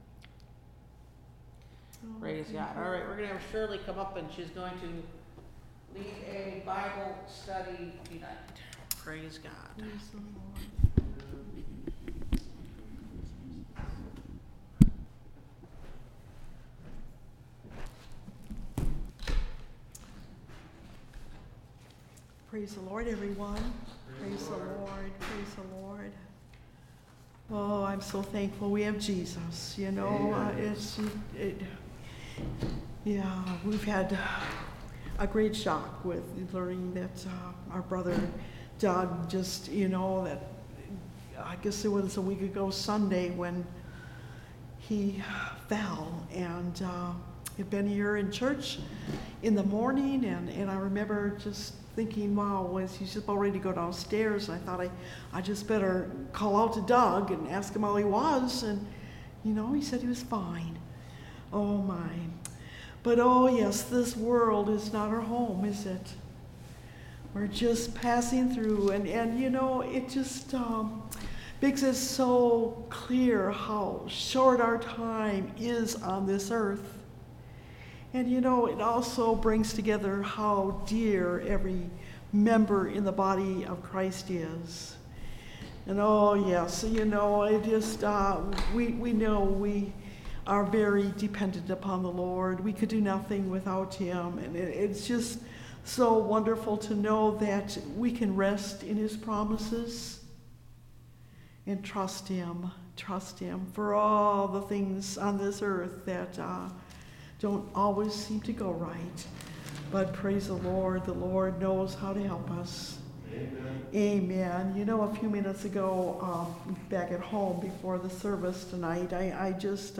Service Type: Wednesday Night Bible Study